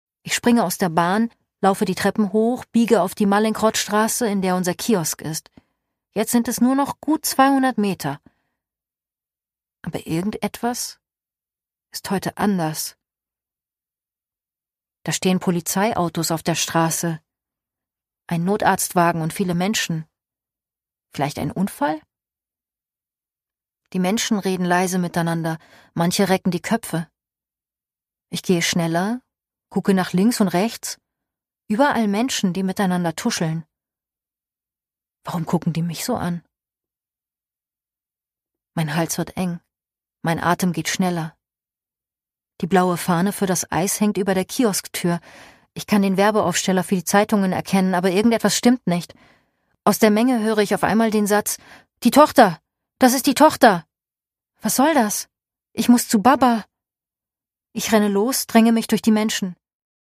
Produkttyp: Hörbuch-Download
Erzählendes Sachhörbuch ab 14 Jahren über die Morde des NSU, authentisch und emotional erzählt.